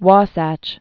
(wôsăch)